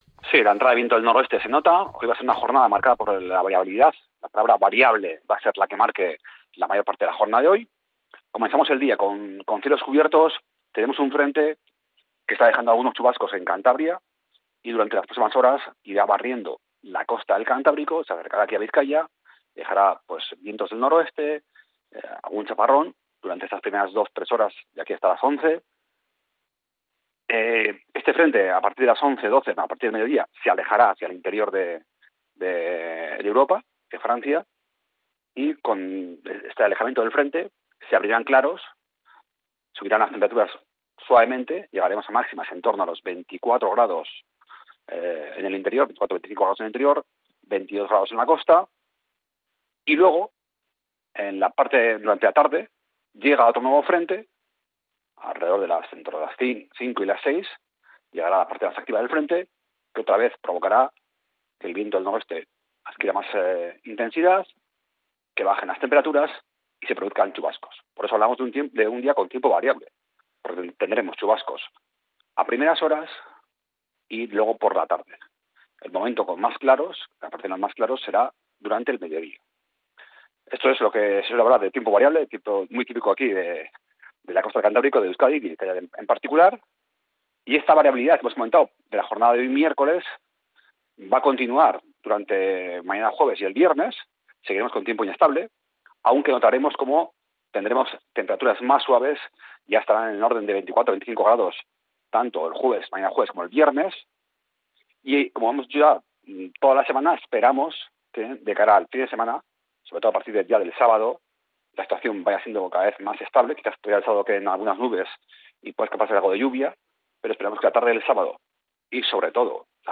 El pronóstico del tiempo en Bizkaia para este 10 de septiembre